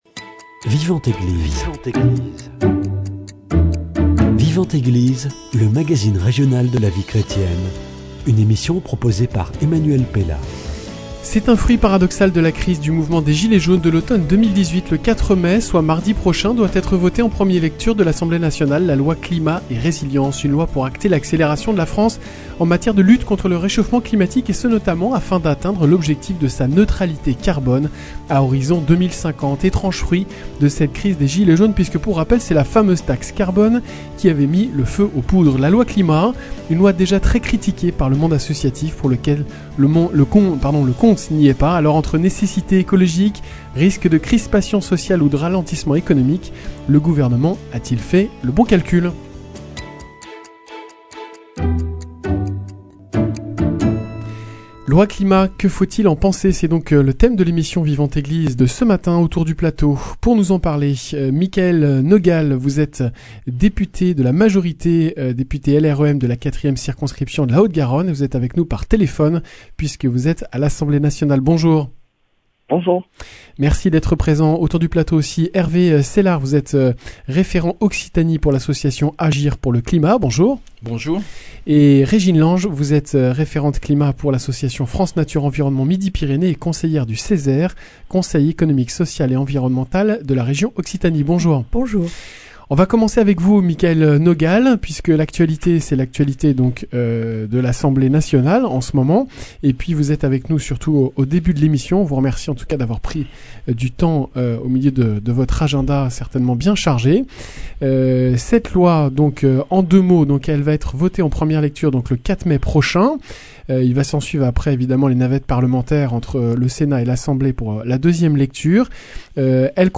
Avec Micheal Nogal, député LRME de la 4eme circonscription de la Haute-Garonne